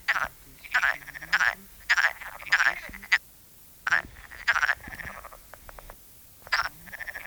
Los sonidos grabados se conservan en la Fonoteca Zoológica del MNCN que contiene más de 52.000 registros sonoros de anfibios y otros animales.
rana_comun.wav